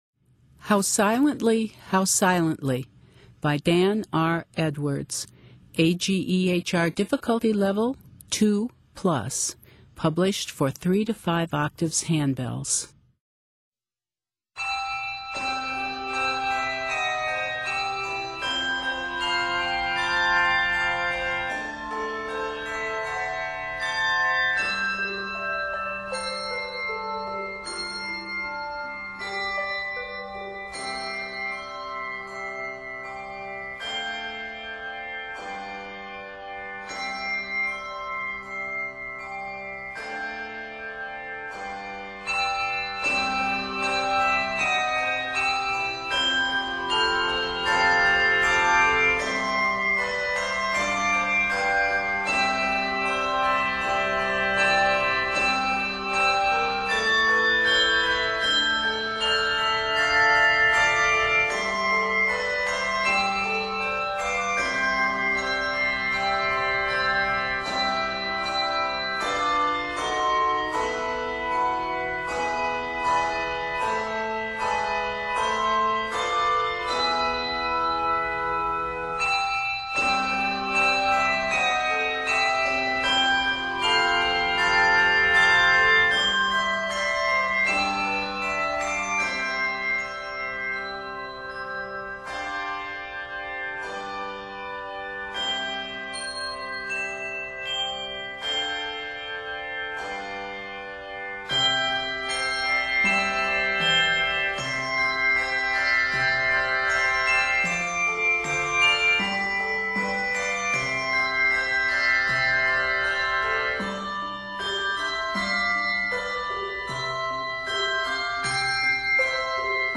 lovely Christmas arrangement full of warmth and tenderness
Scored in C Major